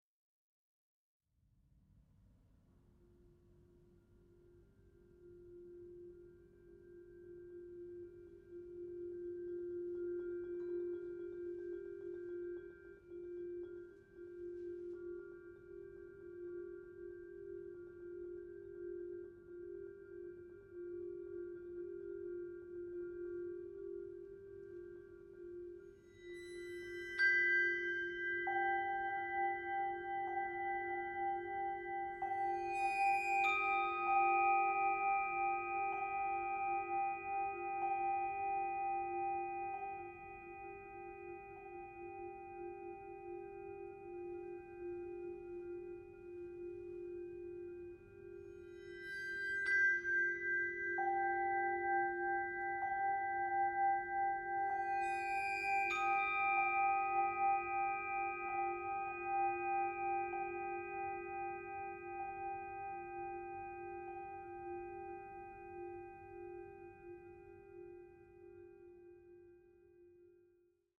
contemplative and inward facing